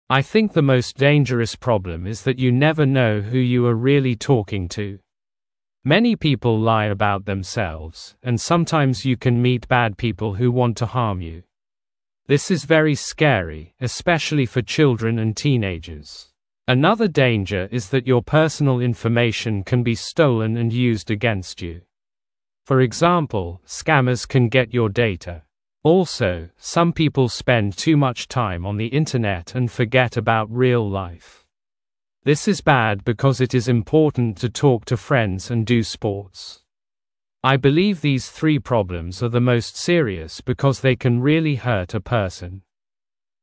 Произношение: